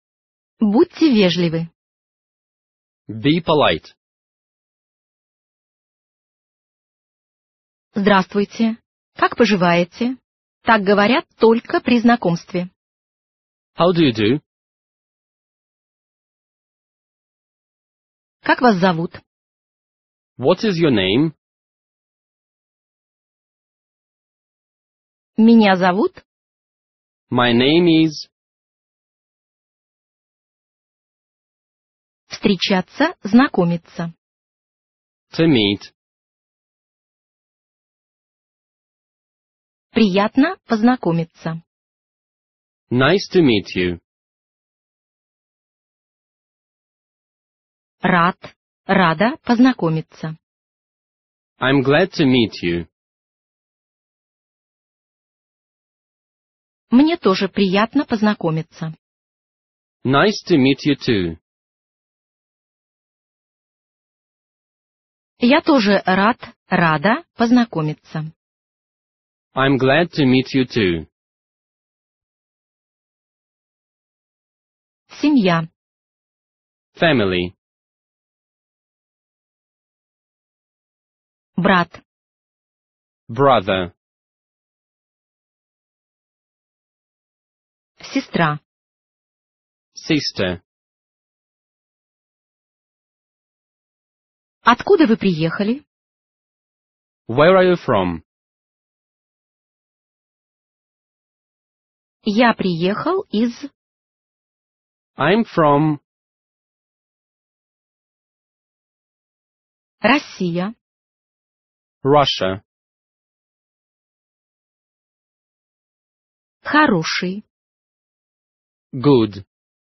Аудиокнига Английский язык для отдыха за рубежом | Библиотека аудиокниг
Aудиокнига Английский язык для отдыха за рубежом Автор Коллектив авторов Читает аудиокнигу Профессиональные дикторы.